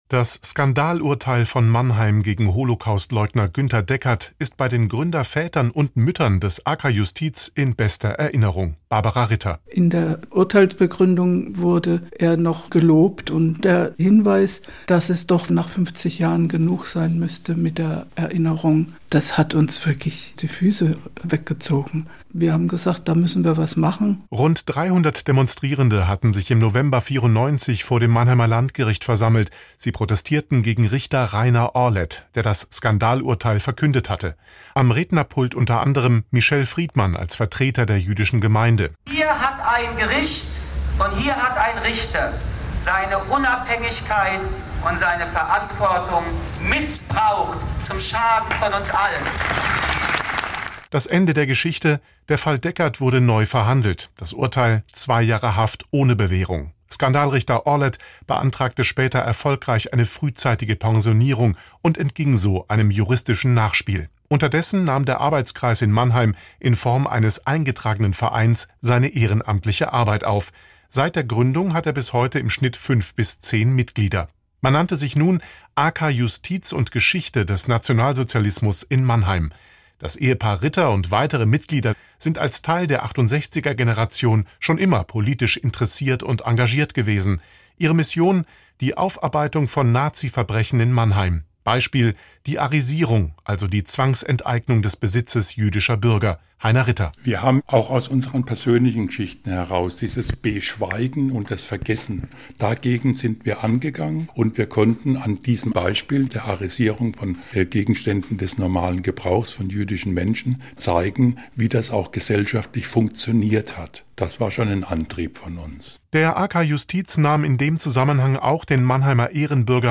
vortrag-im-marchivum-das-skandalurteil-von-mannheim-1994-und-seine-folgen.m.mp3